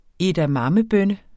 Udtale [ edaˈmɑmə- ]